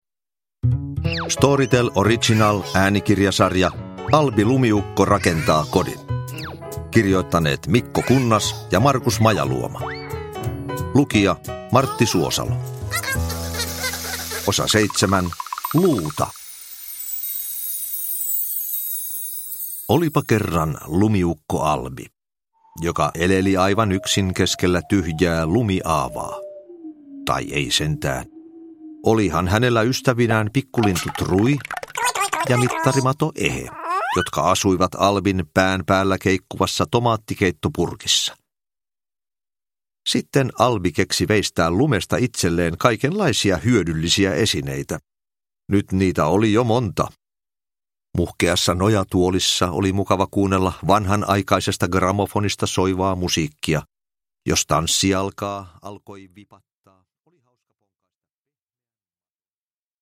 Albi rakentaa kodin: Luuta – Ljudbok – Laddas ner
Uppläsare: Martti Suosalo